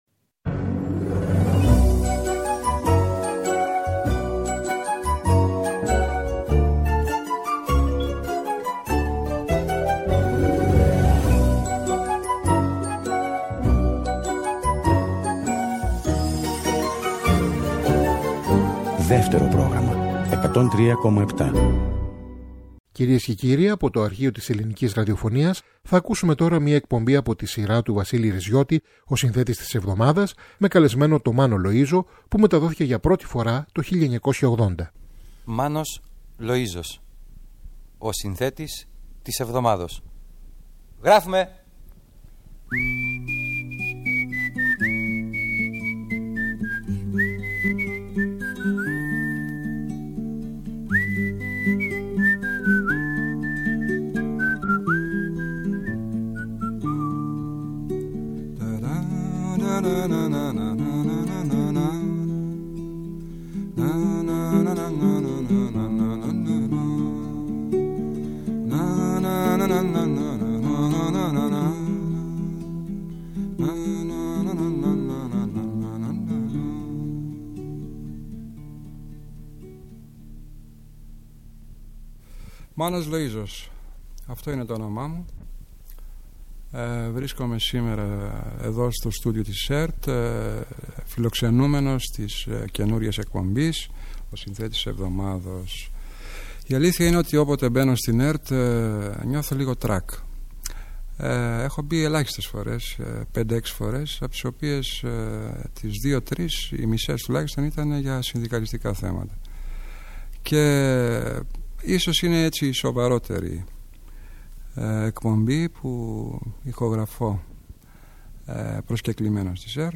παίζει στην κιθάρα και τραγουδά κάποια από τα τραγούδια του.